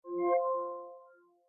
unlock.mp3